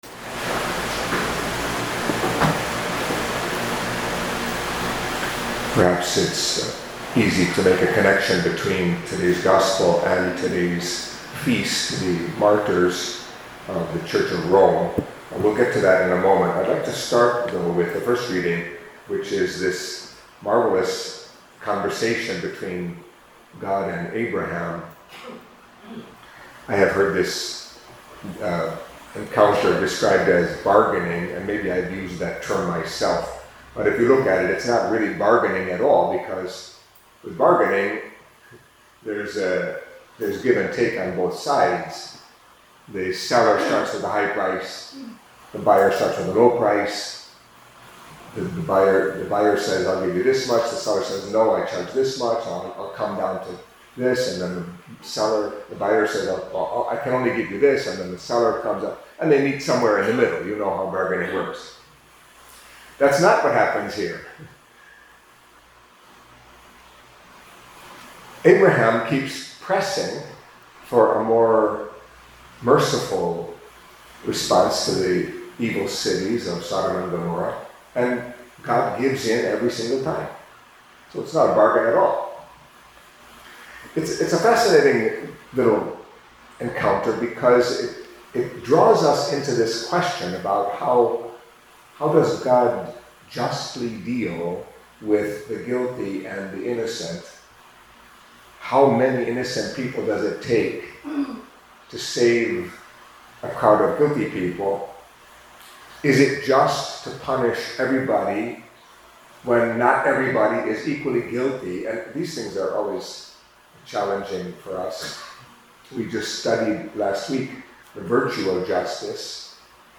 Catholic Mass homily for Monday of the Thirteenth Week in Ordinary Time